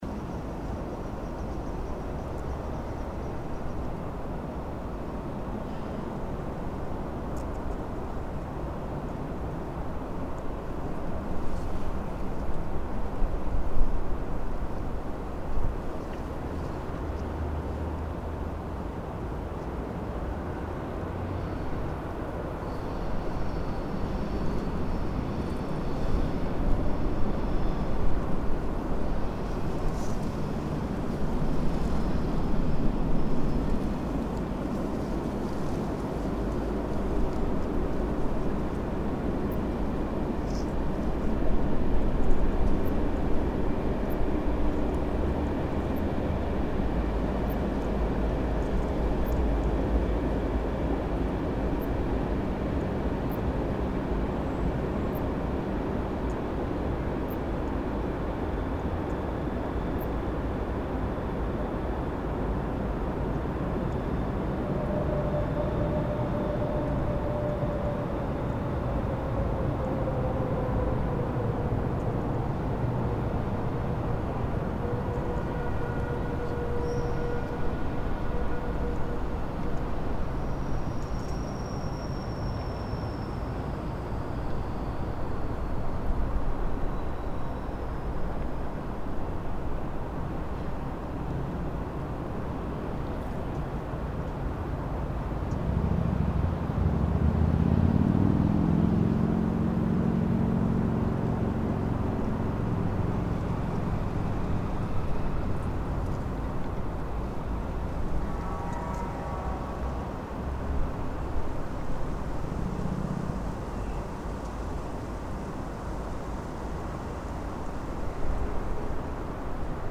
the eight recordings that constitute "soundmap vienna" originate partly from locations inside the city that bear an everyday relationship with me and partly have been "discovered" during a few occasional strolls. what they have in common is that they don’t bear an apparent reference to vienna, neither acoustically nor visually – no fiakers and no vienna boys choir, sorry for that – and that most of them were taken at unusual times during the day. these recordings were left unprocessed and subsequently arranged into a 19-minute long collage. the second, more experimental collage was composed out of midi-data and soundfiles that have been extracted via specific software from the photographs taken at the recording sites.